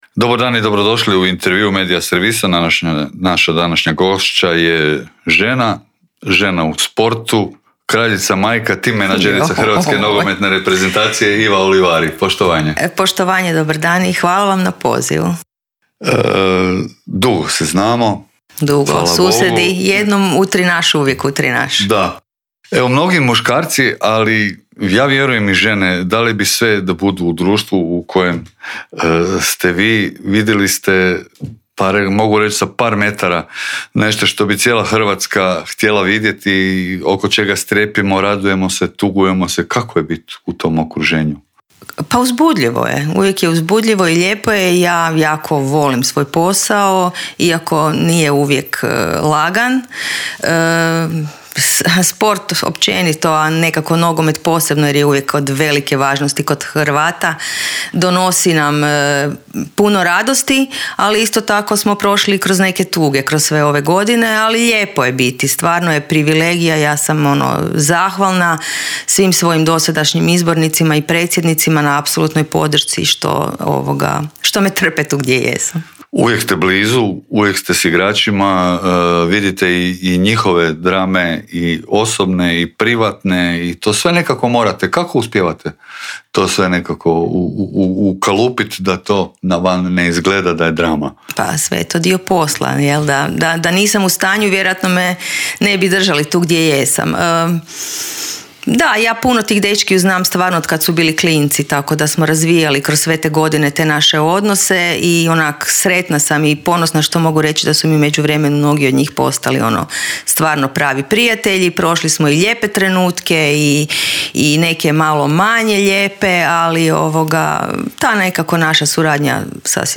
Intervjua